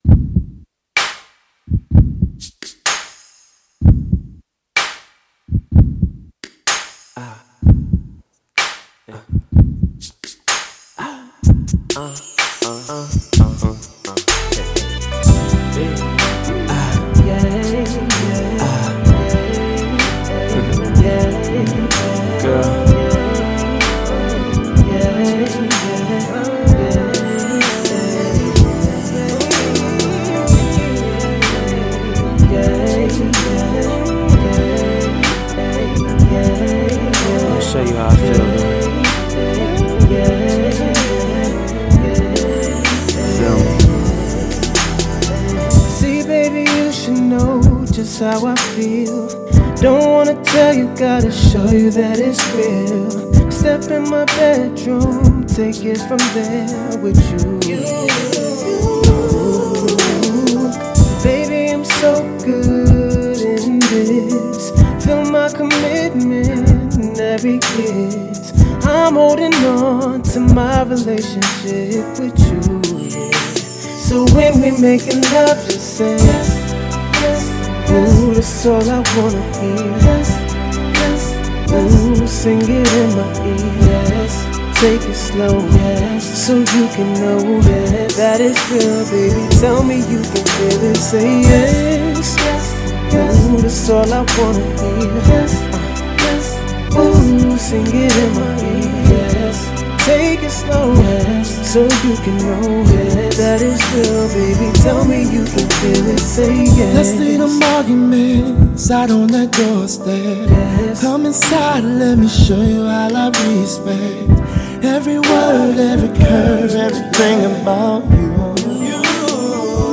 I don't really know much about this duo.